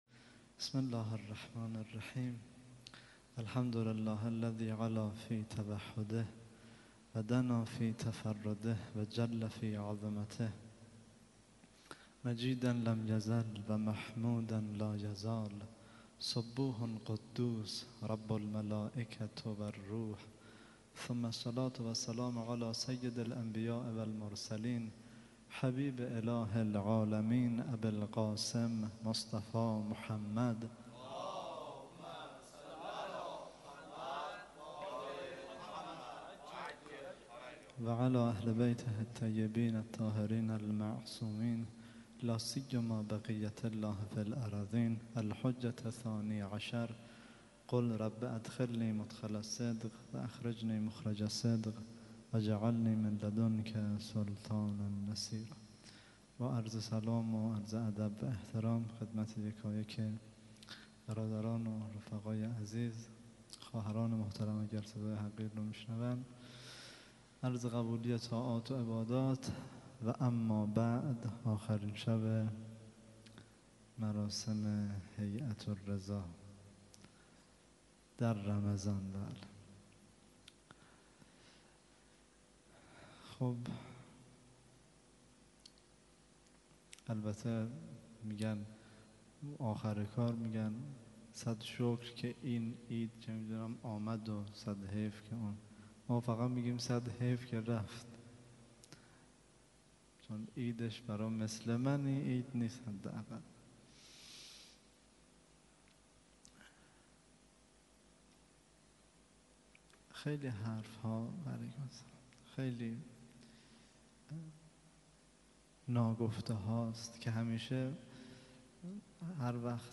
0سخنرانی